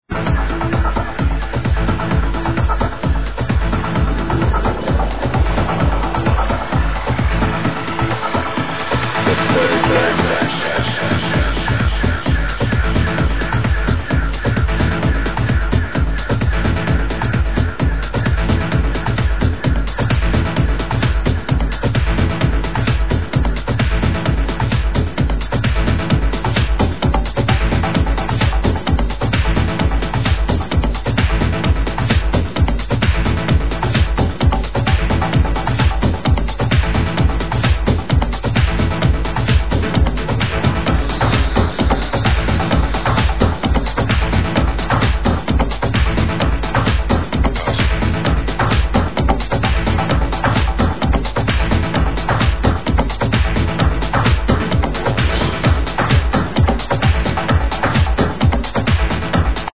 ---- 2005 Prog House